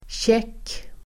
Ladda ner uttalet
Uttal: [tjek:]